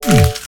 object_delete.wav